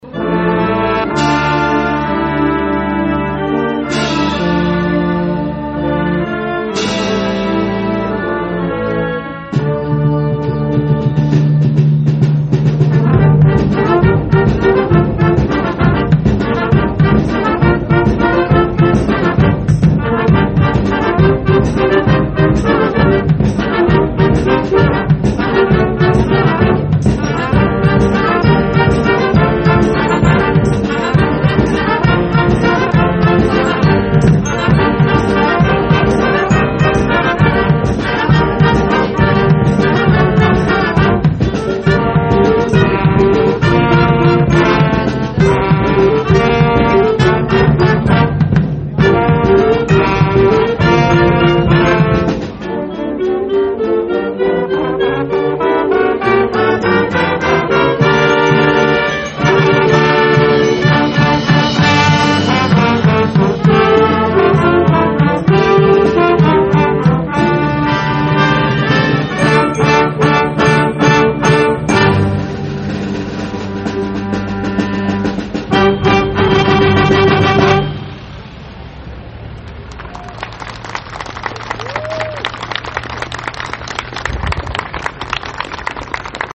A cada fim de uma música muitas palmas de aprovação do público que ficou bem acomodado em cadeiras para escutar o melhor da música internacional e nacional.
A Rádio Colmei, a gravou um trecho da primeira música e toca para você ouvir e deixa o convite junto com o grupo do SIM, para ver mais uma apresentação no dia 2 de setembro, no Cine Teatro Ópera, com entrada gratuita.
SIM-02-MÚSICA-ENTOADA-NO-ENSAIO.mp3